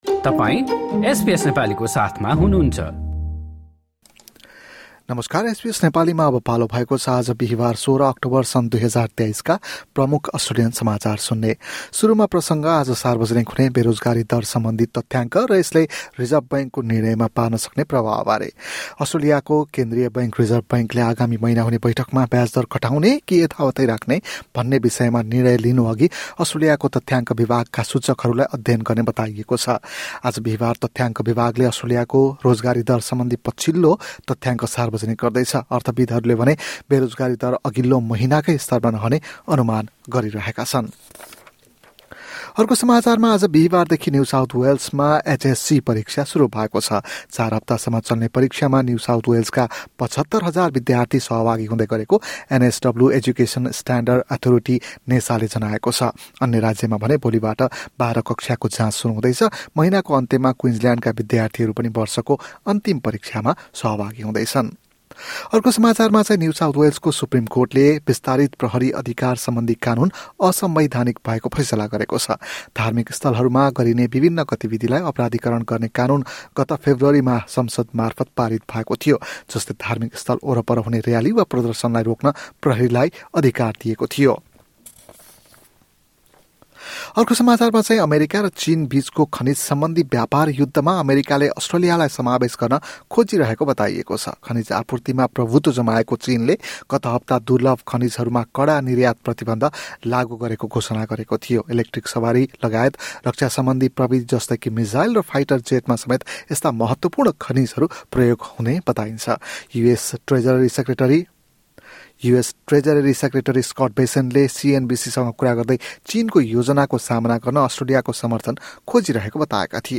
एसबीएस नेपाली प्रमुख अस्ट्रेलियन समाचार: बिहीवार, १६ अक्टोबर २०२५